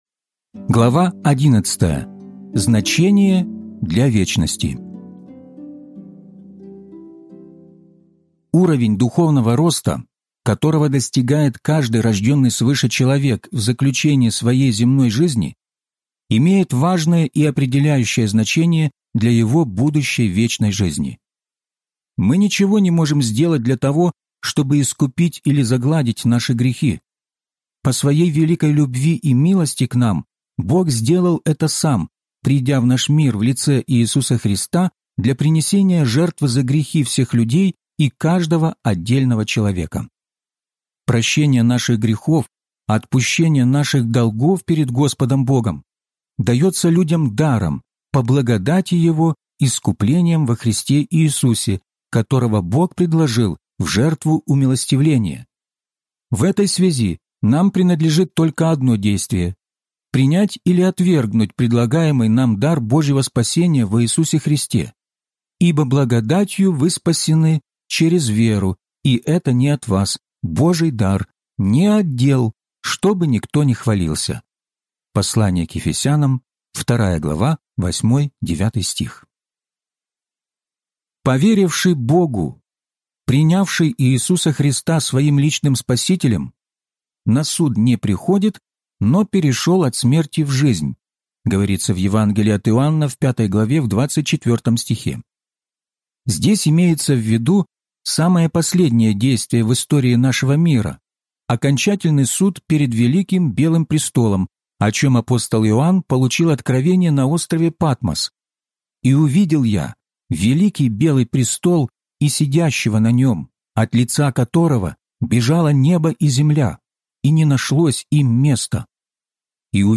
Возрастайте! (аудиокнига) - День 32 из 34